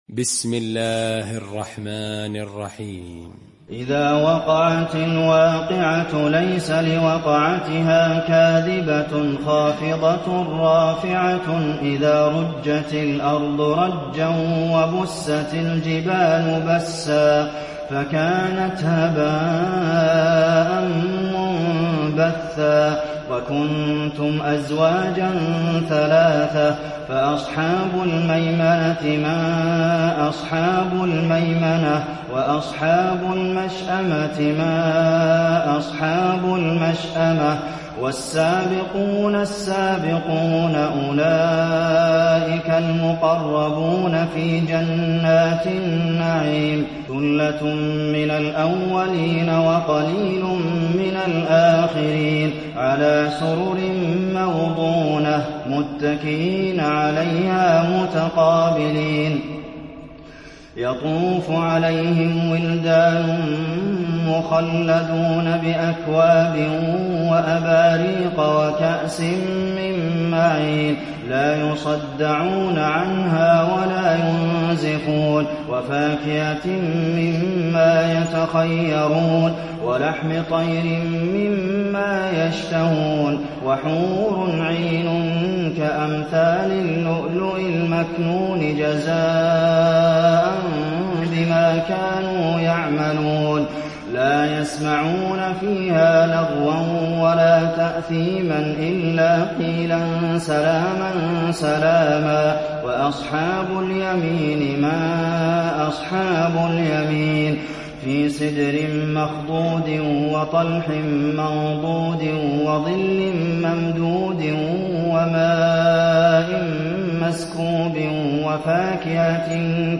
المكان: المسجد النبوي الواقعة